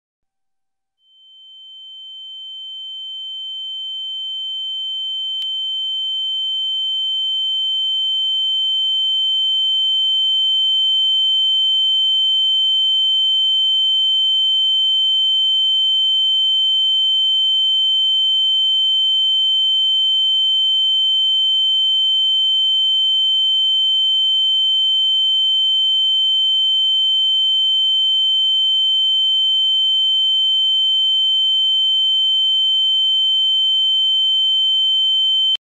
Ear Ringing Sound Effect Free Download
Ear Ringing